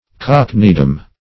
Search Result for " cockneydom" : The Collaborative International Dictionary of English v.0.48: Cockneydom \Cock"ney*dom\ (k[o^]k"n[i^]*d[u^]m), n. The region or home of cockneys; cockneys, collectively.
cockneydom.mp3